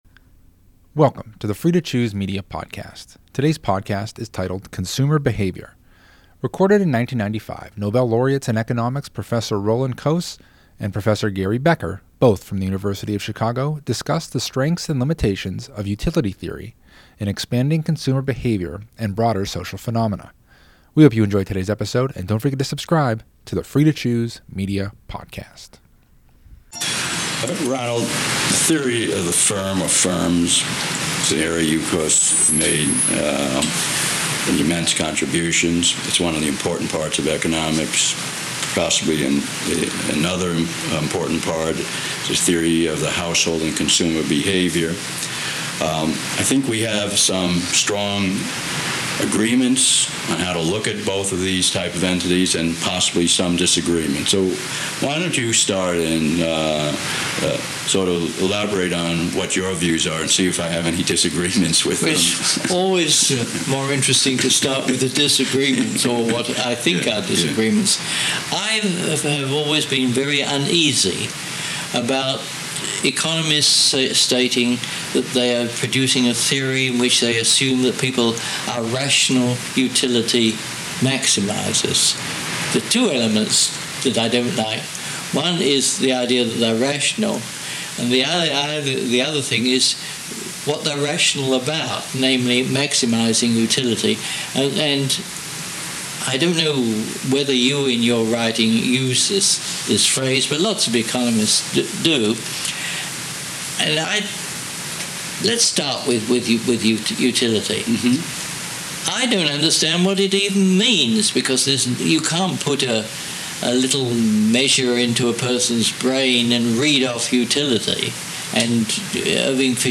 Recorded in 1995, Nobel laureates in Economics, Professor Ronald Coase (1991) and Professor Gary Becker (1992), both from the University of Chicago, discuss the strengths and limitations of utility theory in explaining consumer behavior and broader social phenomena.